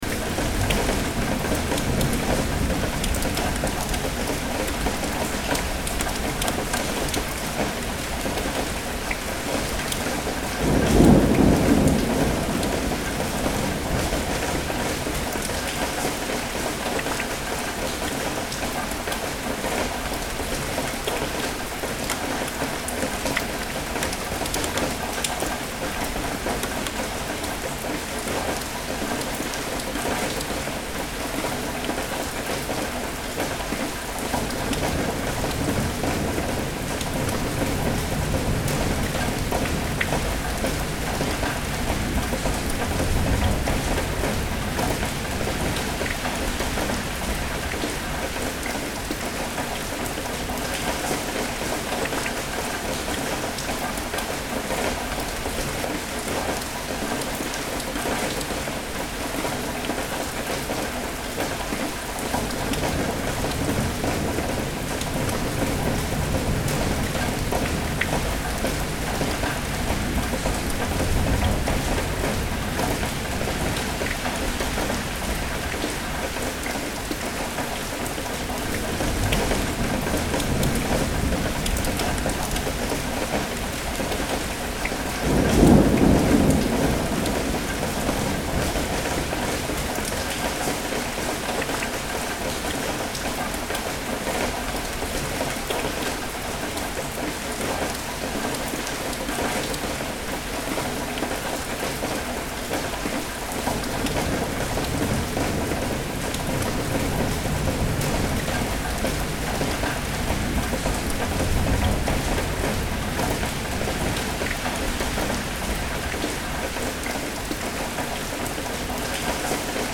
Rain-thunder-sounds-for-sleeping.mp3